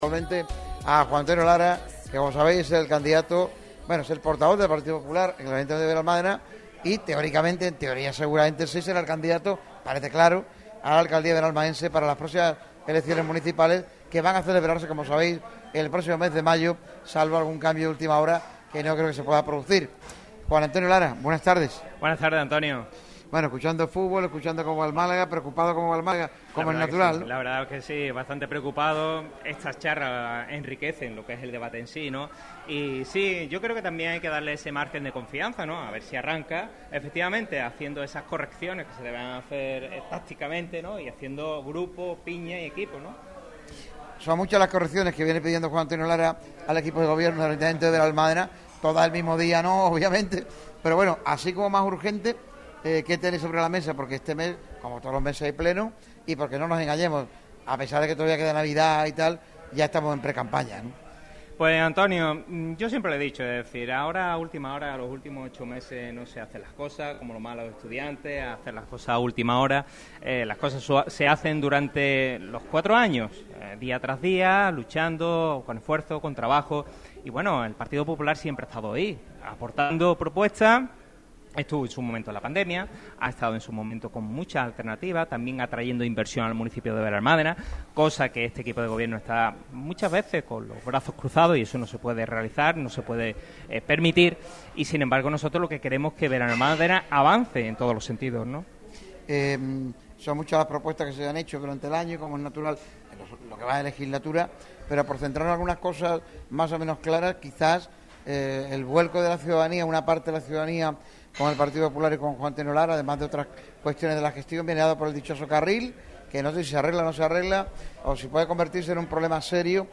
El candidato del PP a la alcaldía de Benalmádena, Juan Antonio Lara pasó por los micrófonos rojos de Radio MARCA Málaga en las instalaciones del VIPS Sport Bar de Torremolinos